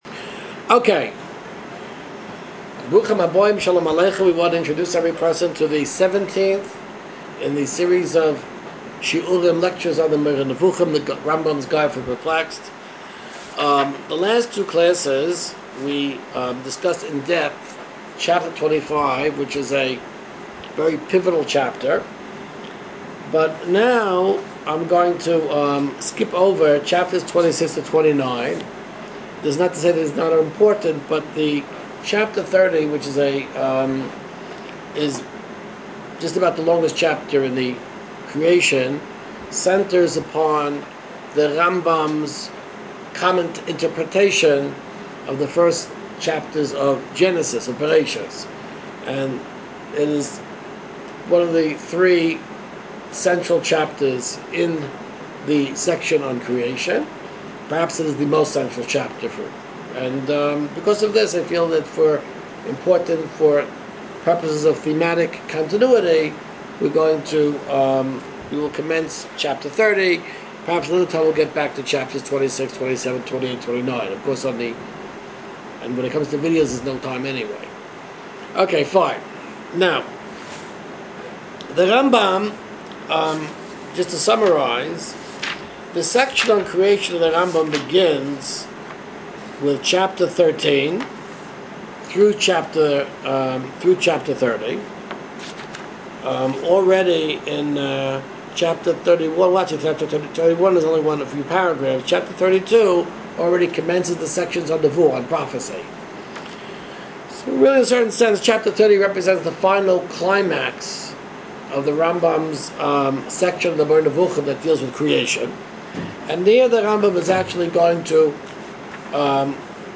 Here are the next four shiurim from last year’s course